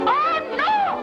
Worms speechbanks
Whatthe.wav